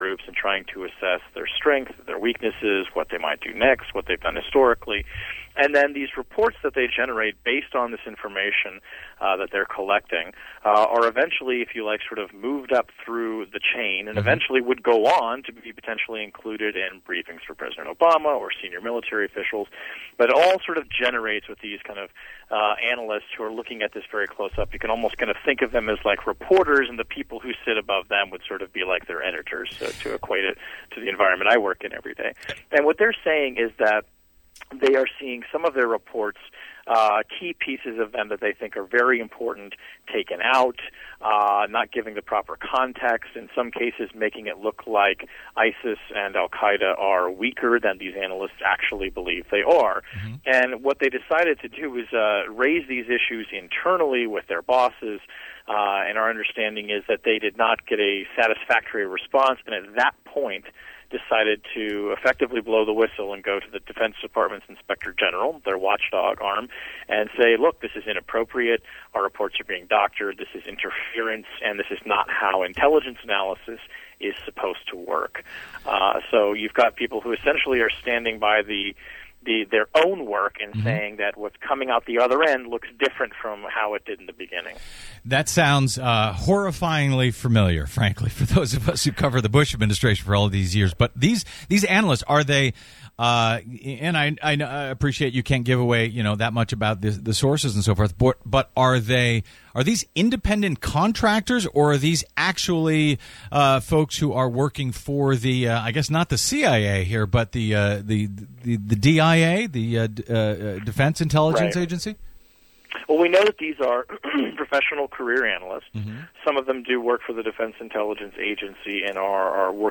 Download audio file On Saturday September 12th, Kshama Sawant spoke in Portland. Here is a recording of that talk. From the streets of Baltimore to the growing movement for a $15 minimum wage, consistent protests are erupting against the endemic inequality and racism generated by Wall Street...